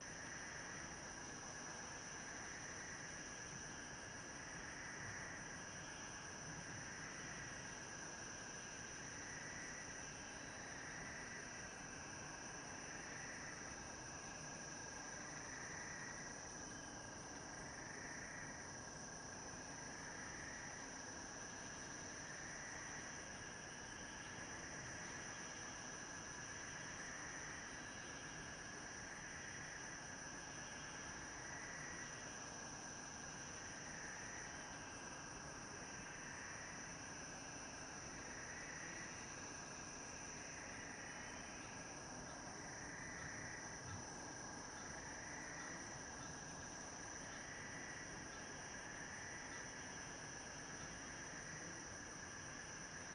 base-wind-gleba.ogg